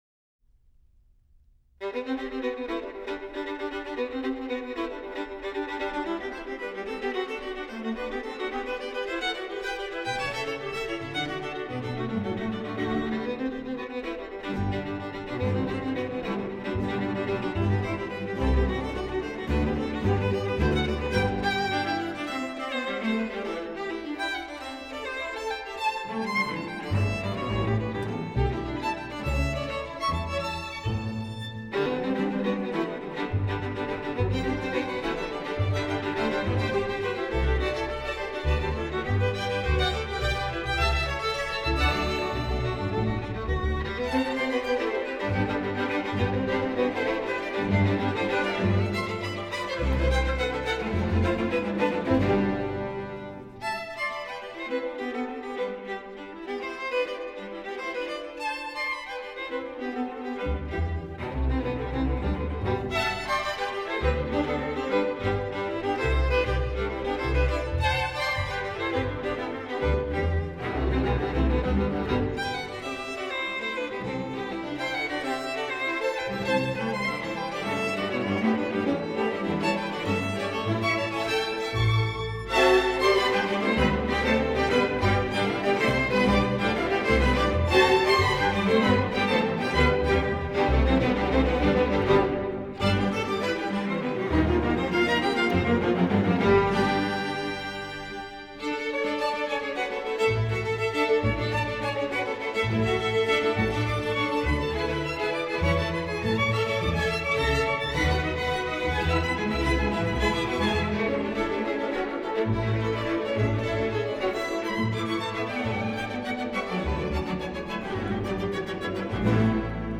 for violin and orchestra